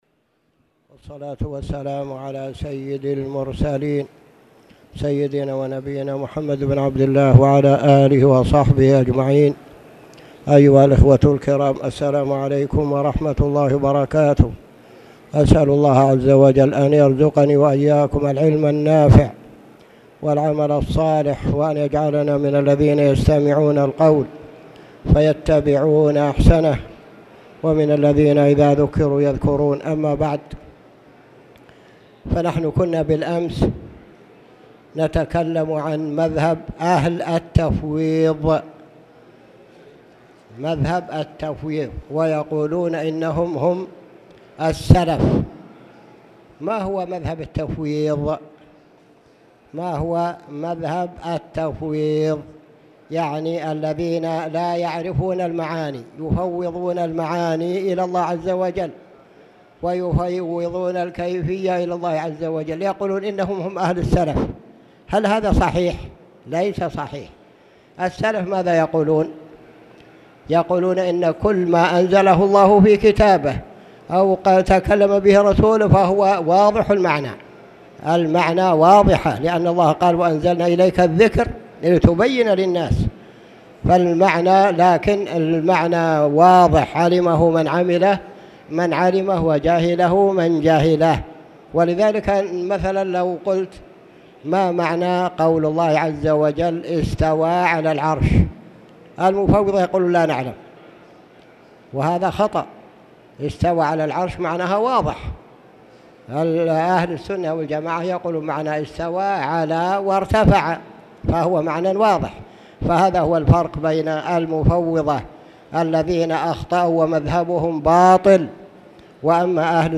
تاريخ النشر ٨ صفر ١٤٣٨ هـ المكان: المسجد الحرام الشيخ